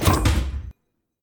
tank-door-open-1.ogg